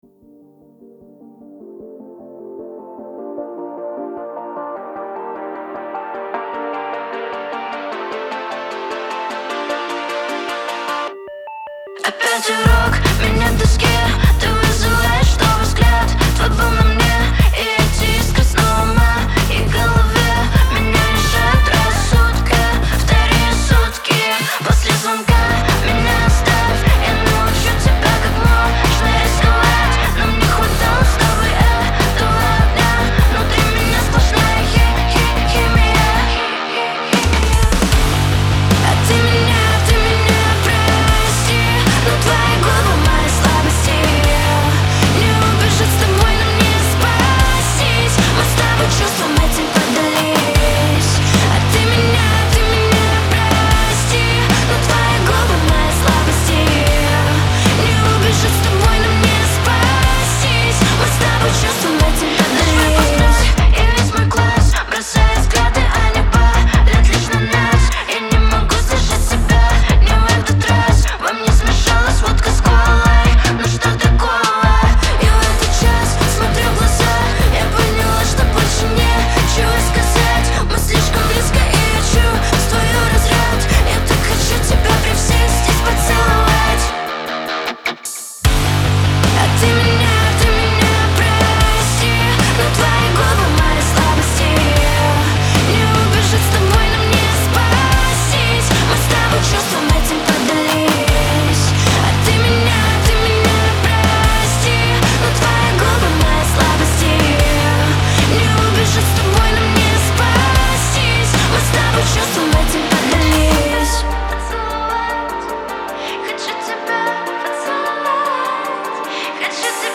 отличается мелодичными ритмами и запоминающимся припевом